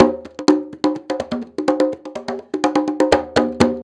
Live Percussion A 05.wav